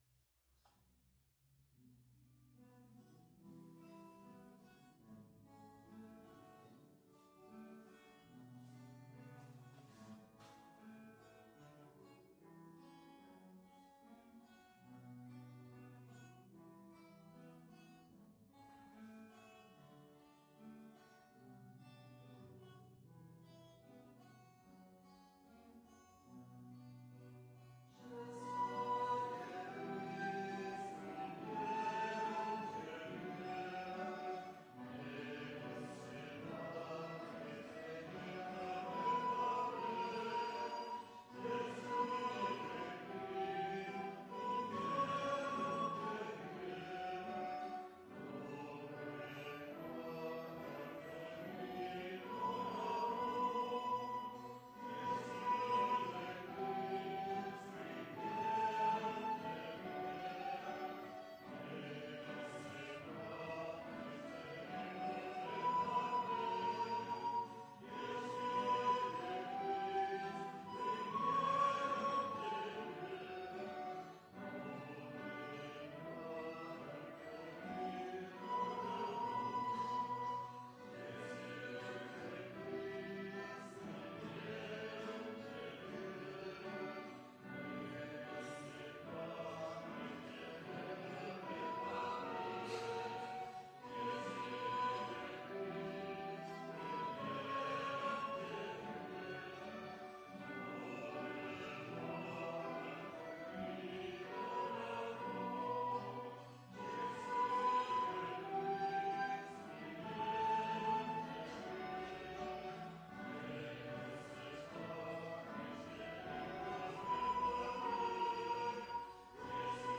Ermita de Sant Simó - Diumenge 27 de gener de 2019